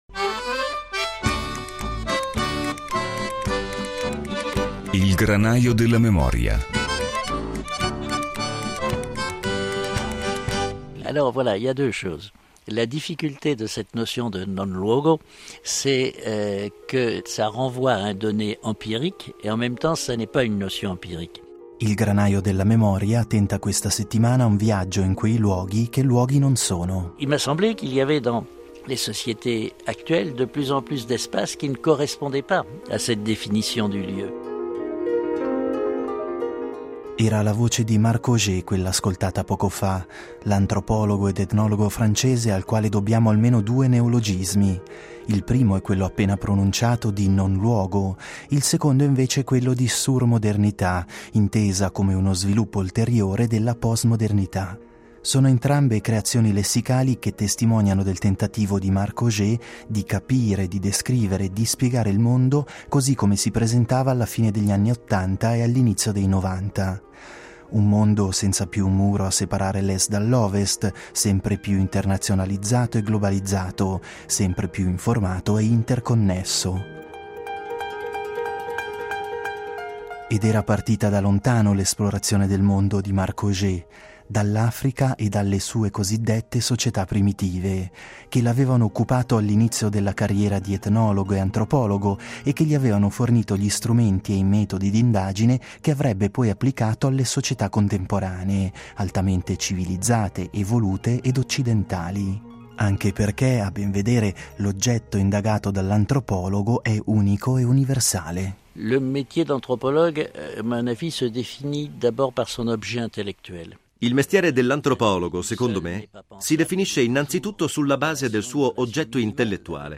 La voce di Marc Augé è in effetti rintracciabile tra le teche, visto che i microfoni della RSI lo hanno intervistato più volte, per meglio capire che cosa davvero fossero i non luoghi.